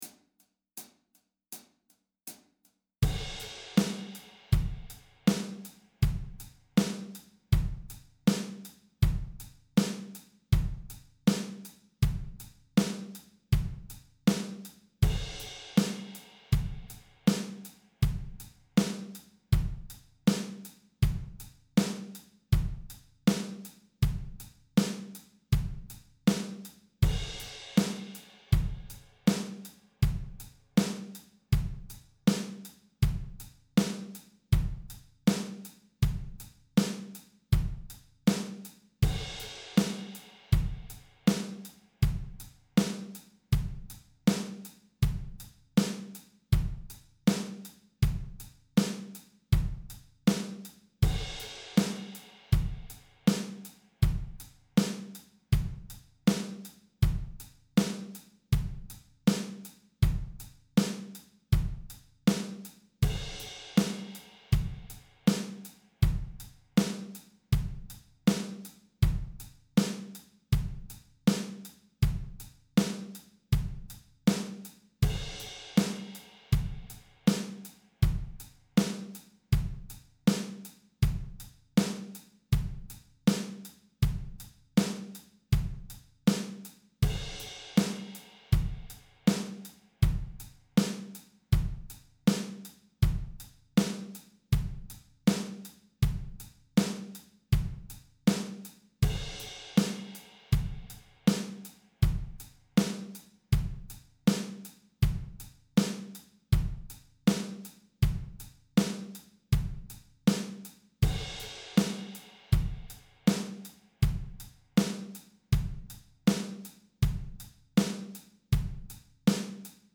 Drum Tracks for Extra Practice
With that in mind, I put together some basic bass drum & snare combos that evoke the AC/DC sound.
Slow (80bpm) - download, or press the play button below to stream:
584-drums-80bpm.mp3